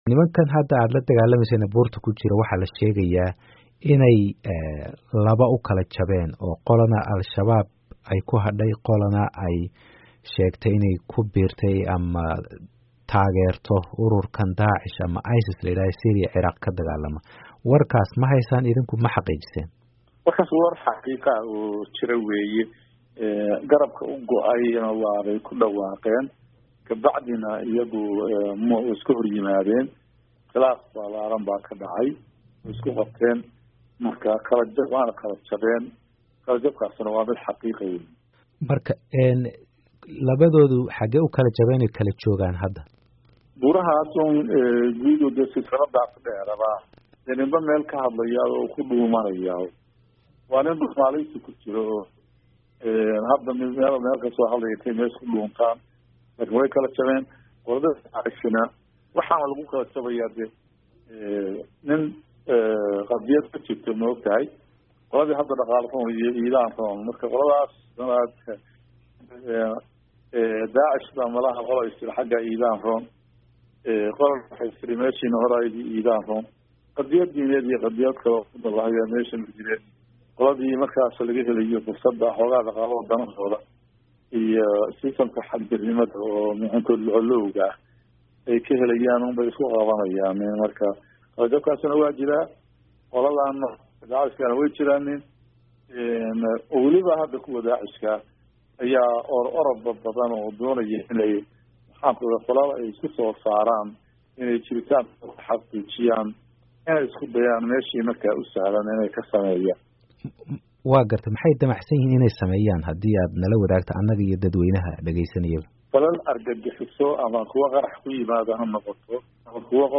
Taliyaha Guud ee ciidamada Puntland Jeneraal Siciid Maxamed Xirsi oo waraysi khaas ah siiyey idaacadda VOA ayaa sheegay in ciidankoodu ay diyaar u yihiin wixii weerar ah ee ka yimaada ayna heegan yihiin.